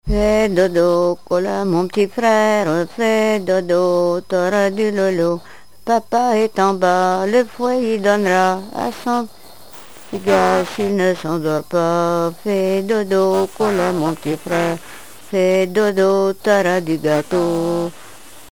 berceuse
Répertoire de chansons traditionnelles et populaires
Pièce musicale inédite